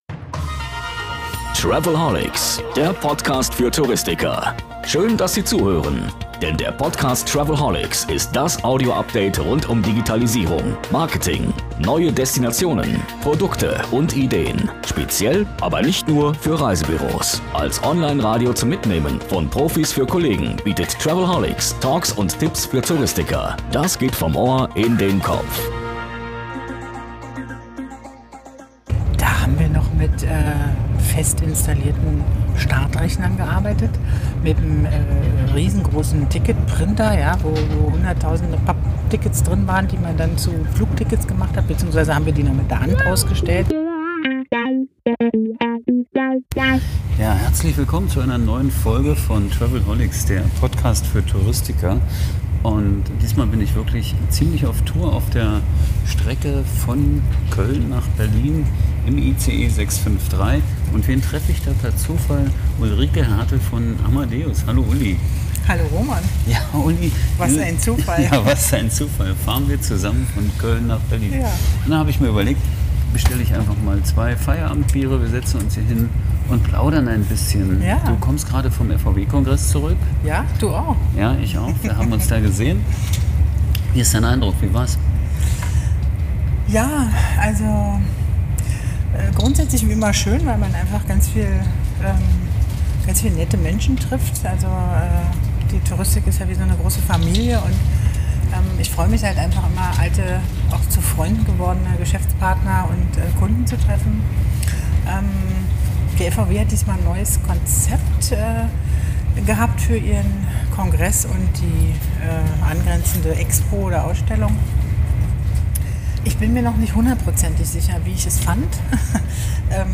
Ein Heimfahrtbier mit angestecktem Mikrofon und 35 Minuten Travelholics-Talk sind bereit, gehört zu werden. Dabei geht es um frische Eindrücke vom Kongress und persönliche Meinungen zu Branchent